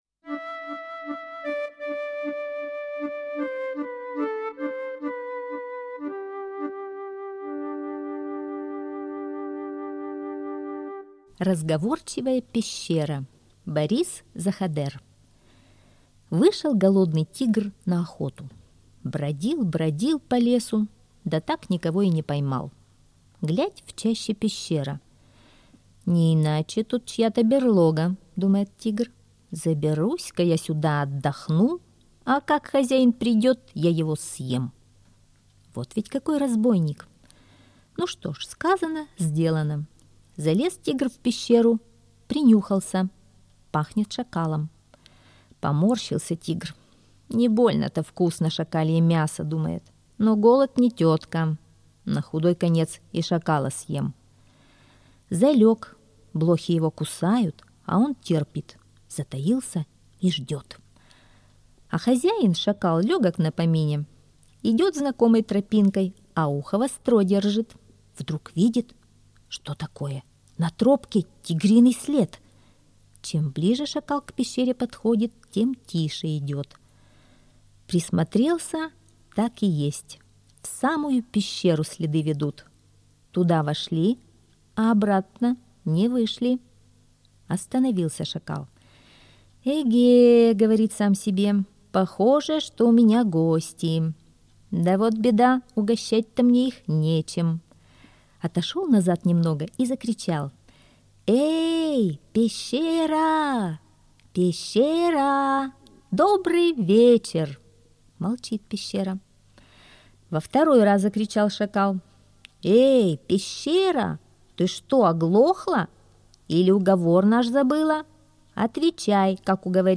Разговорчивая пещера — аудиосказка Заходера Б. Сказка про голодного Тигра, который забрался в пещеру к Шакалу в надежде его съесть...